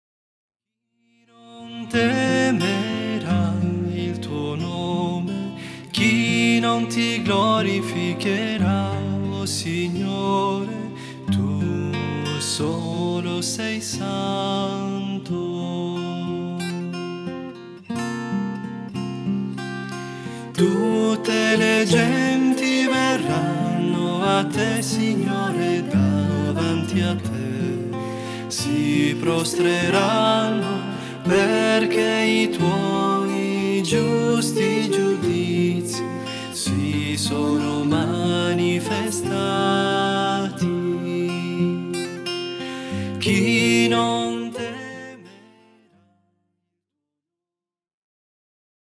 MIDI 4 voix